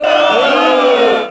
1 channel
booing01.mp3